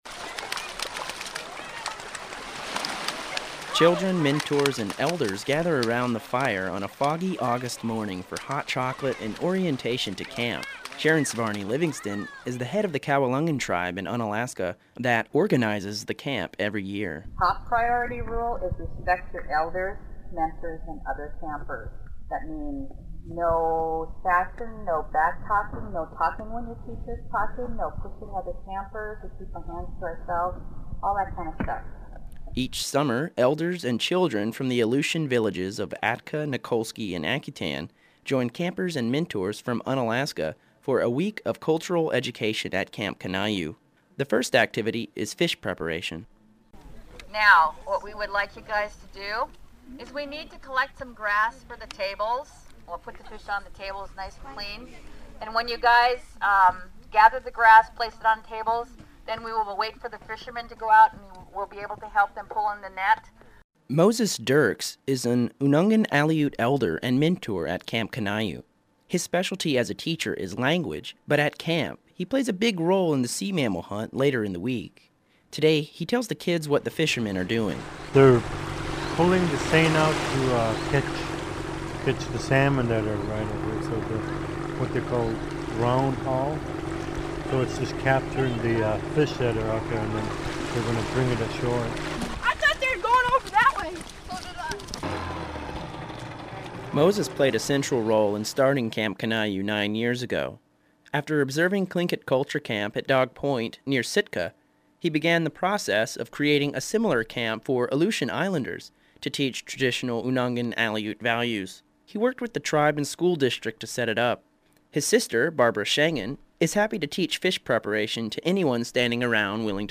(This story first aired on APRN's "AK" program on August 12.)